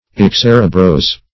Excerebrose \Ex*cer"e*brose`\, a.